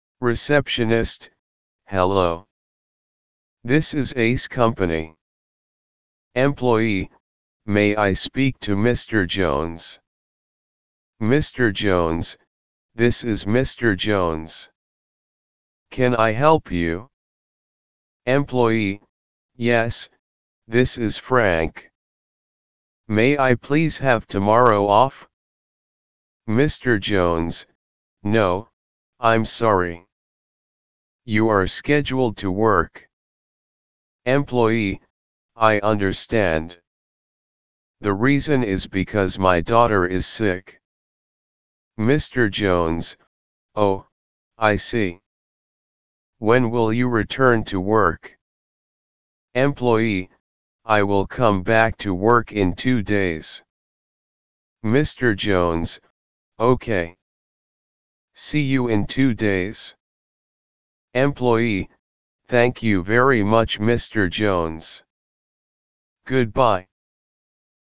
Level 1 Conversation